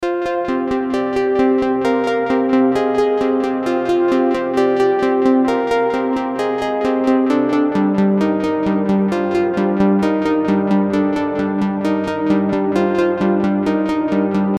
Ethereal Sequencer Synth Loop 132 BPM
Ethereal-sequencer-synth-loop-132-BPM.mp3